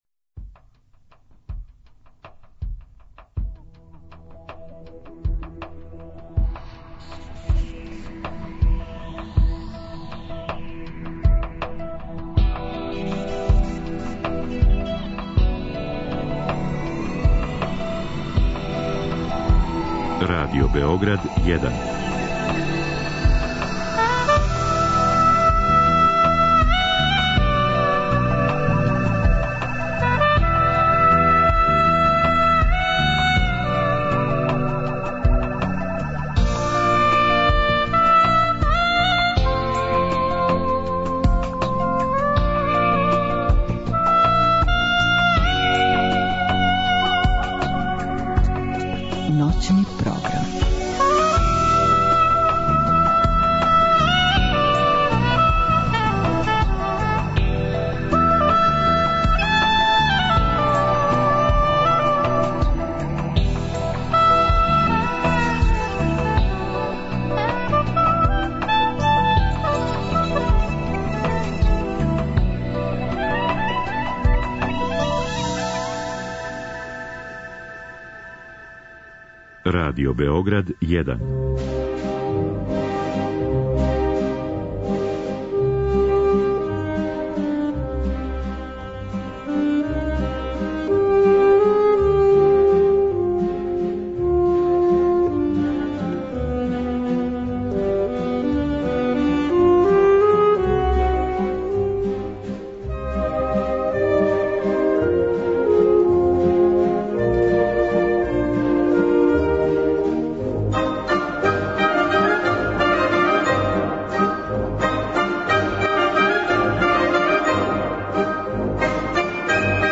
Након прилога, емитоваћемо ово остварење у целини.